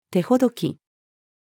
手ほどき-female.mp3